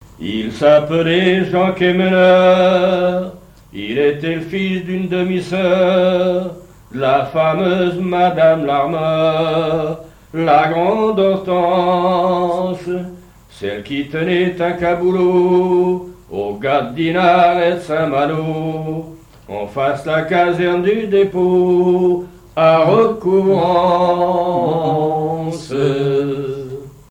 chansons populaires et traditionnelles maritimes
Pièce musicale inédite